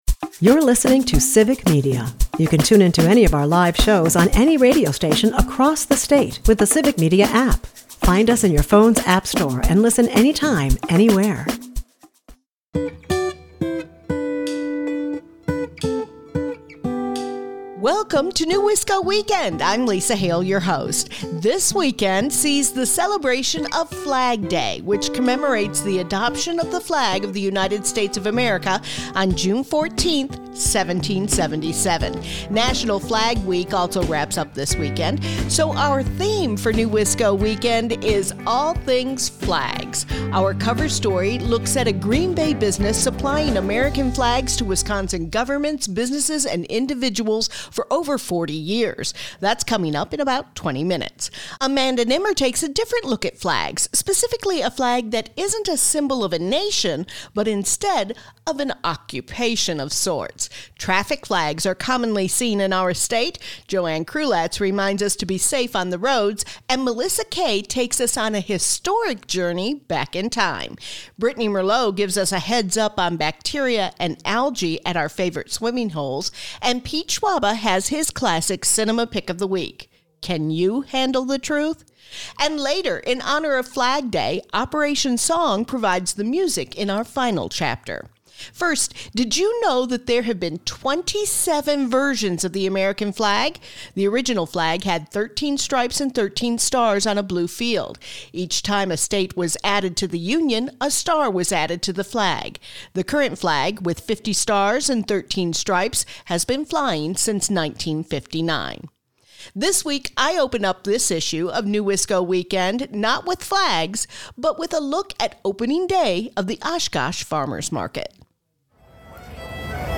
NEWisco Weekend is a part of the Civic Media radio network and airs Saturdays at 8 am and Sundays at 11 am on 97.9 WGBW and 98.3 and 96.5 WISS.